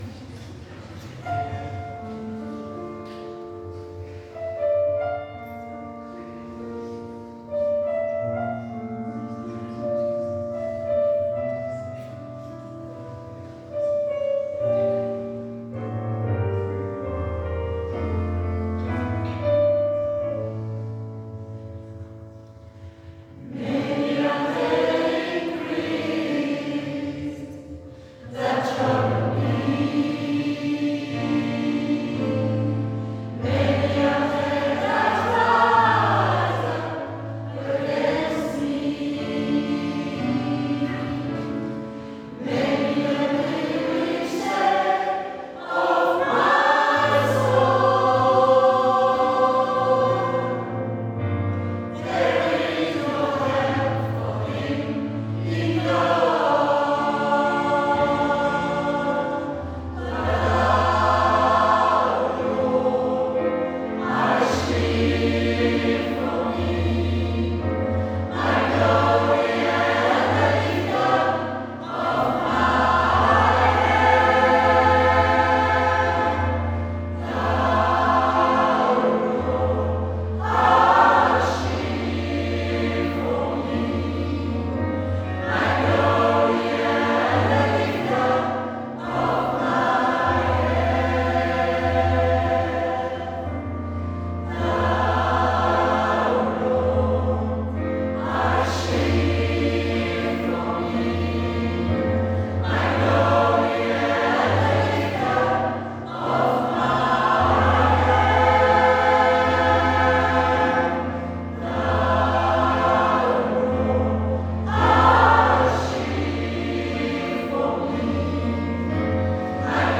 Elle se compose d'une trentaine de choriste
Les chants sont principalement en anglais, et sont chantés par chœur, sans livret ni prompteur.
Le Garden Gospel Group s'est produit à Jardin, à Reventin puis à Sainte-Colombe pour ses 3 concerts de fin d'année.